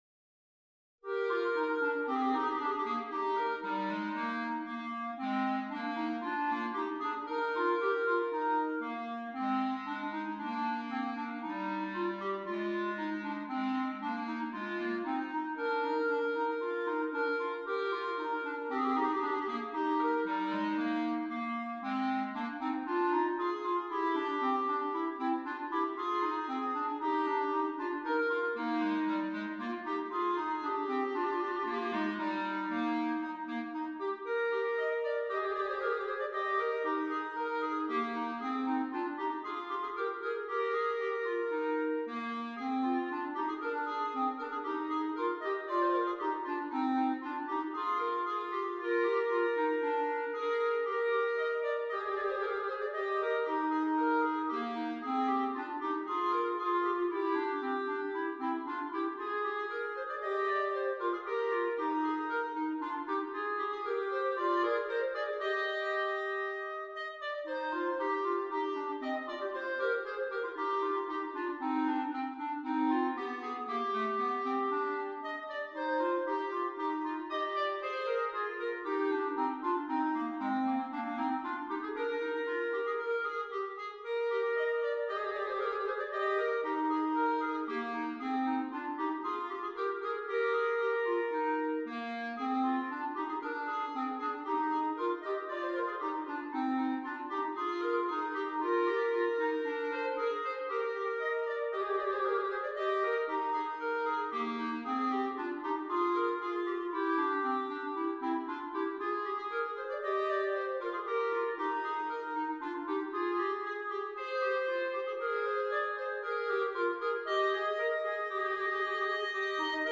2 Clarinets
versatile duet